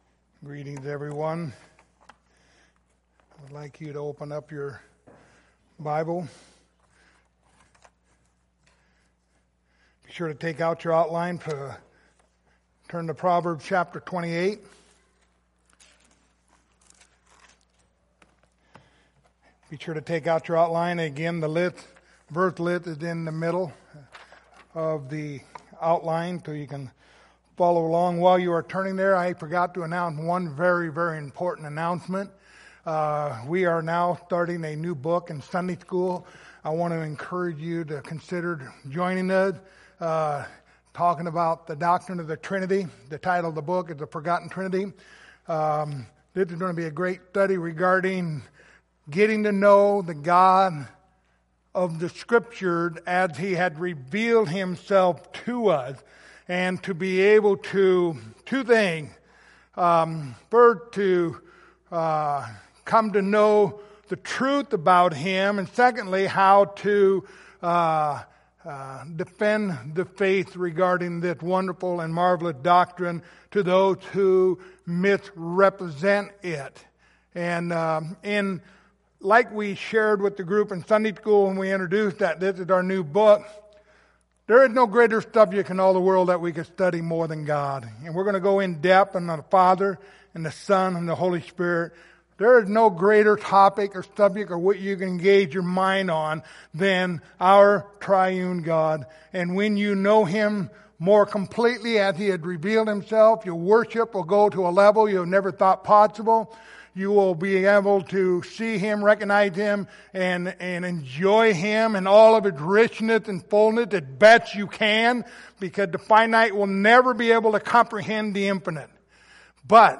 Passage: Proverbs 11:30 Service Type: Sunday Morning